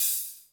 HIHAT HO 8.wav